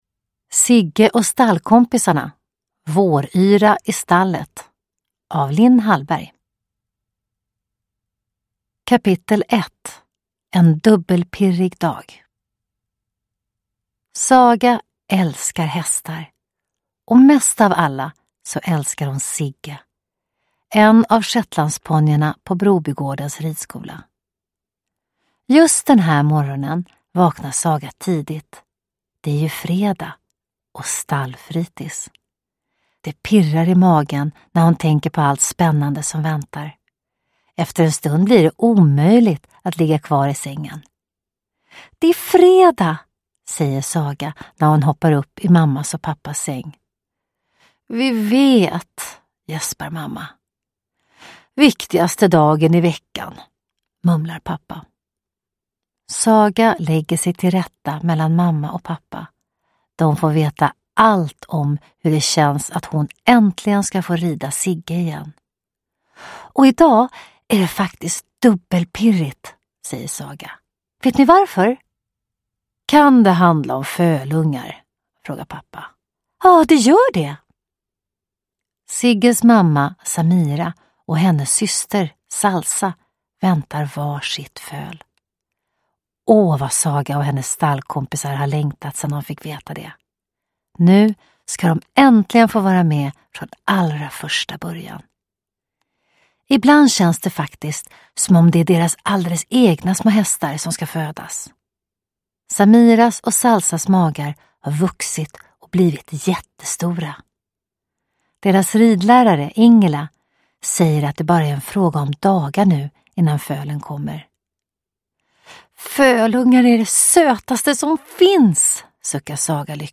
Våryra i stallet – Ljudbok
Uppläsare: Johanna Westman